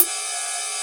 RS RIDE 1.wav